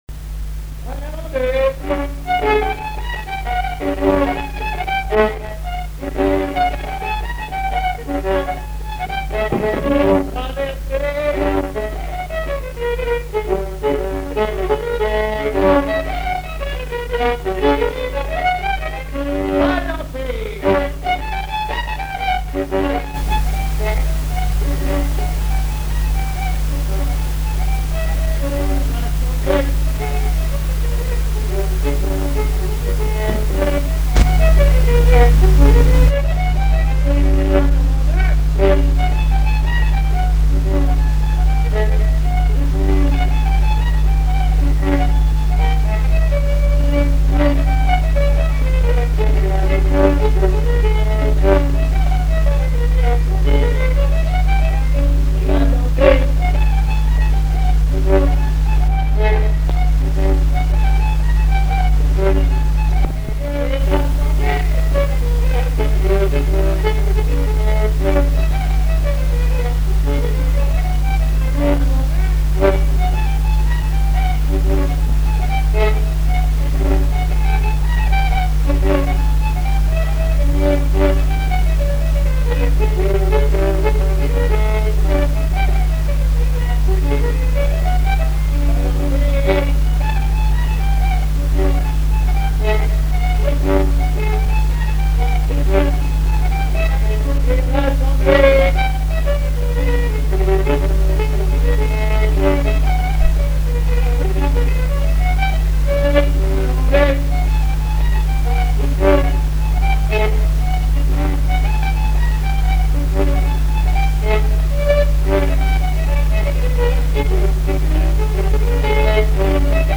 Mémoires et Patrimoines vivants - RaddO est une base de données d'archives iconographiques et sonores.
danse : branle : avant-deux
Répertoire de violoneux
Pièce musicale inédite